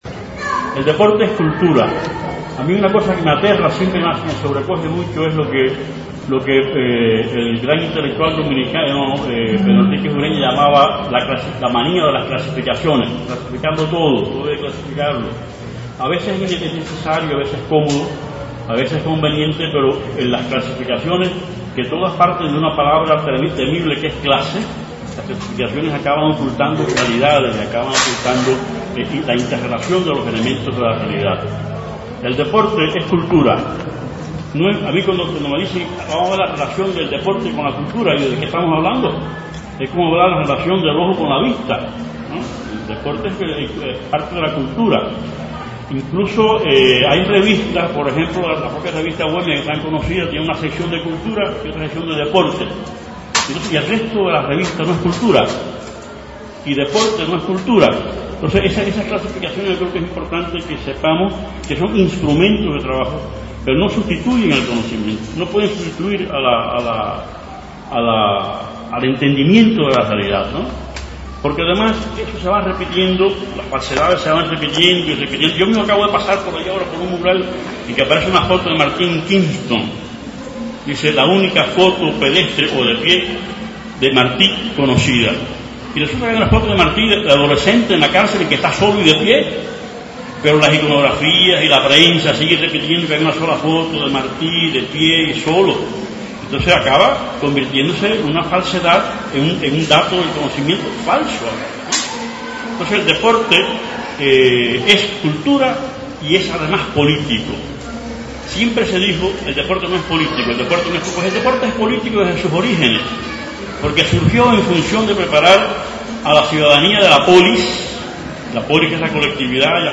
Conferencia